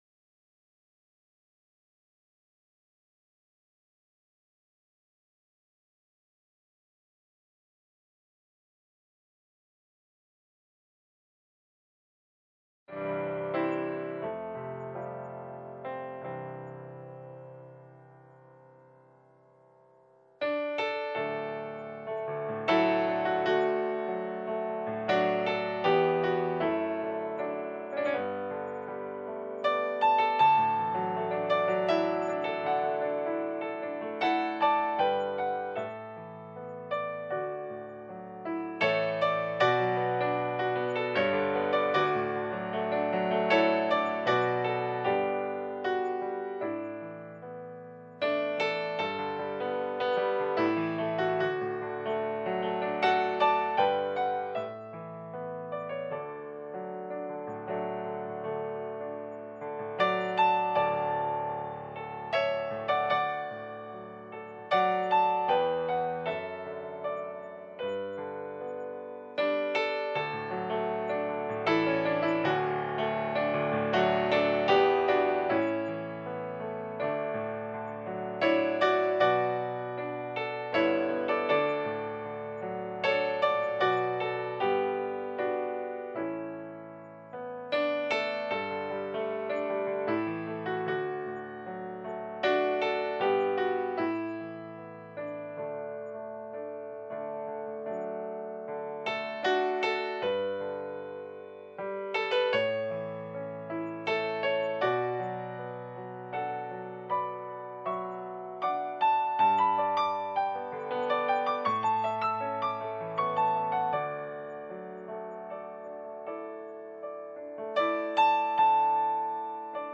From Series: "FBC Pastors"